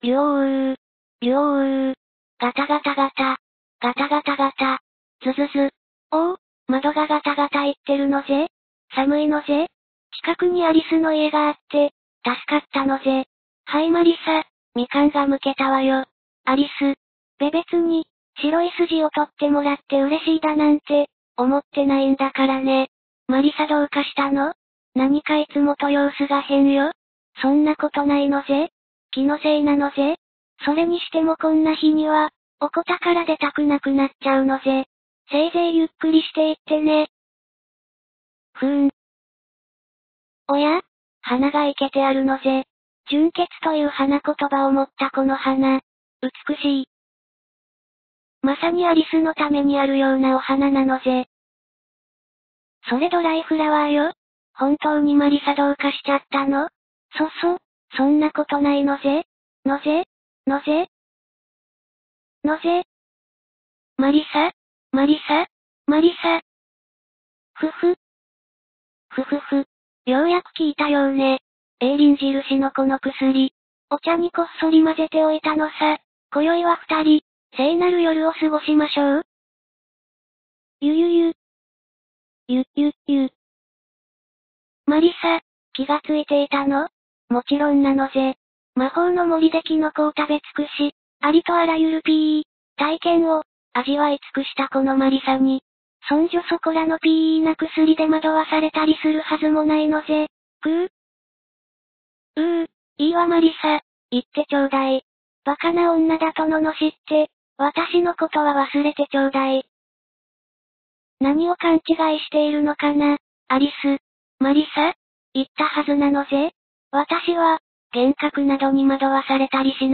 softalkって奴、台無し感が酷い、良い意味で。」